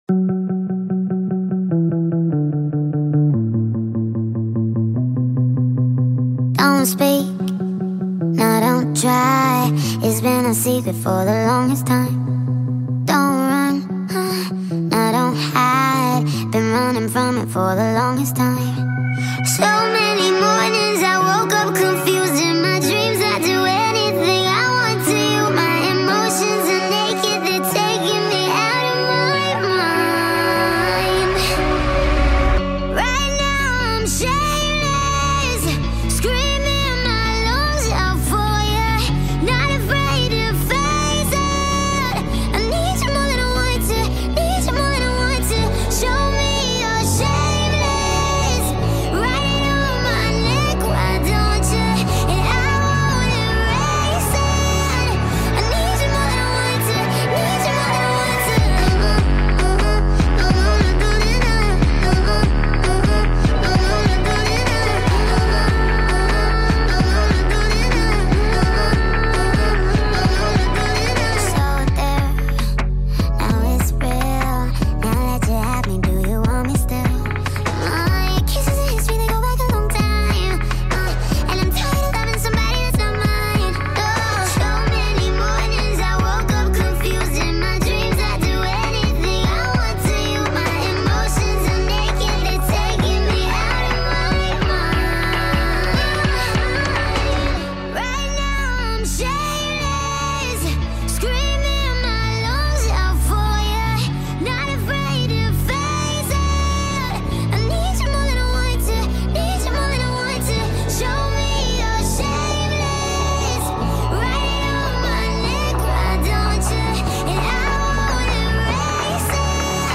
sped up remix